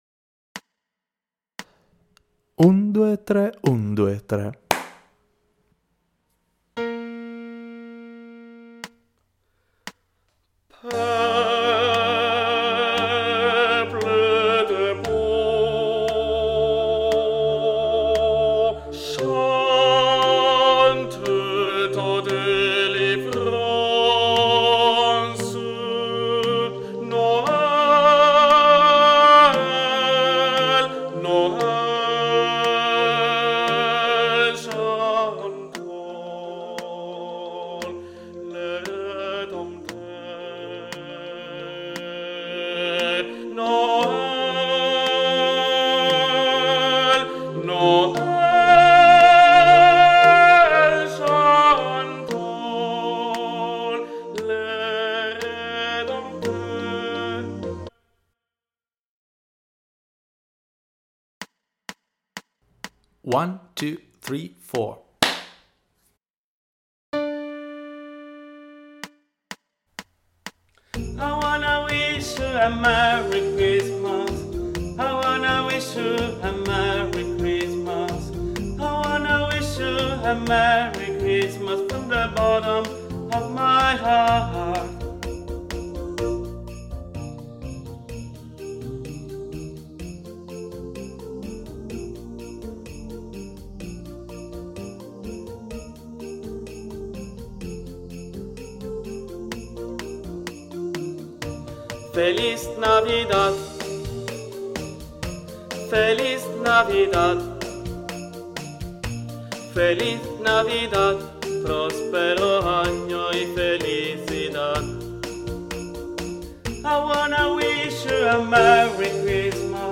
Per partecipare alla nostra iniziativa dovresti studiare la canzone di Natale di Feniarco: un arrangiamento con 10 melodie natalizie, che si alternano tra i diversi registri vocali, realizzato appositamente per l'occasione da Alessandro Cadario.
Mp3 traccia guida coro unisono (TUTTI versione maschile)